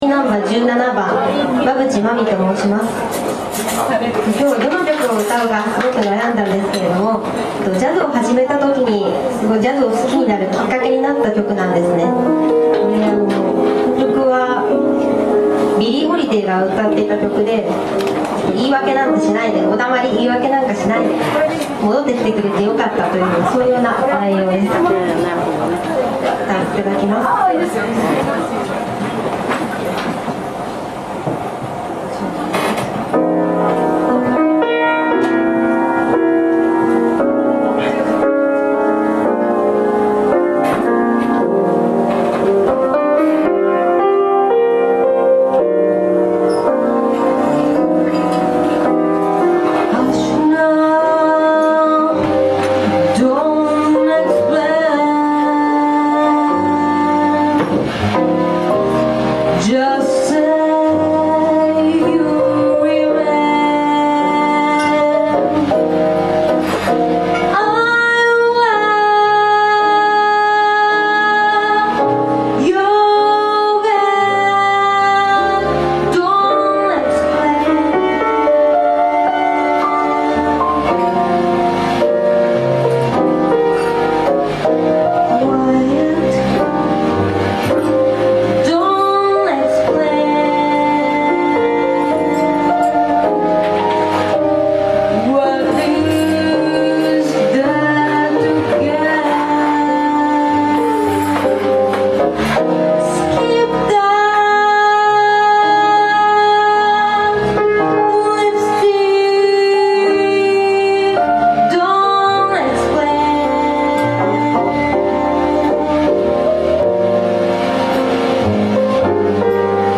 オーディション